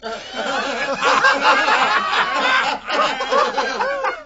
crowd_laugh3_p1.wav